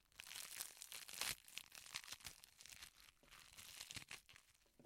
血浆喷射爆裂
描述：捣碎大量的水果和蔬菜。稍有不同的版本。
Tag: 血块 血液 内脏 流血的 血肉模糊 喷射 破裂